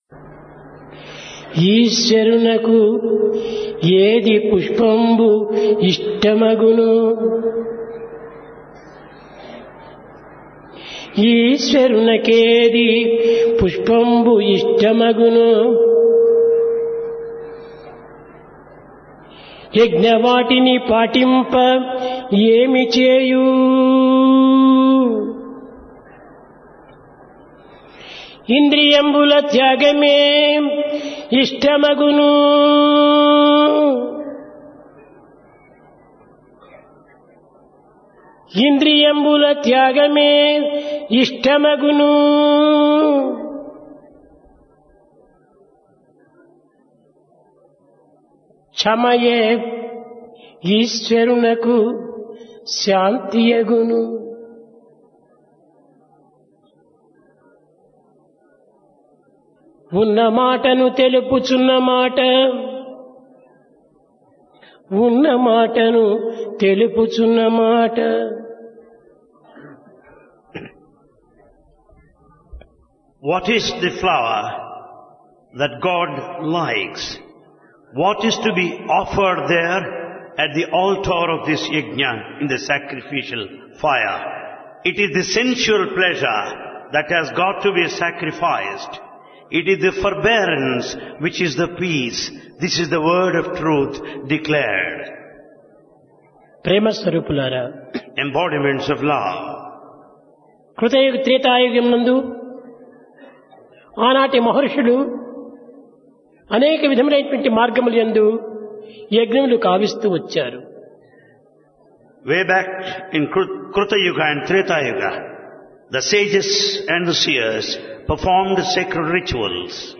Dasara - Divine Discourse | Sri Sathya Sai Speaks
Place Prasanthi Nilayam Occasion Dasara, Vijayadasami